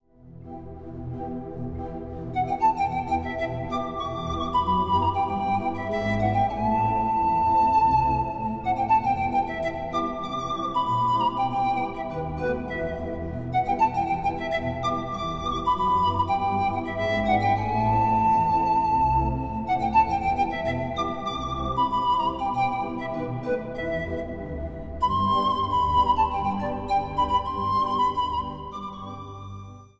Instrumentaal | Panfluit